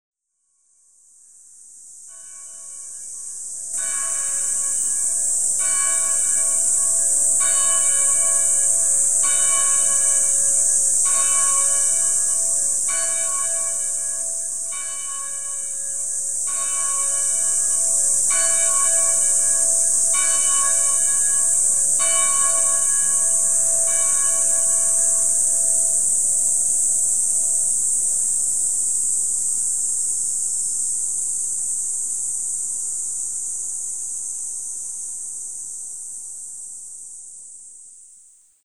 「国際基督教大学」の協会の鐘の音です。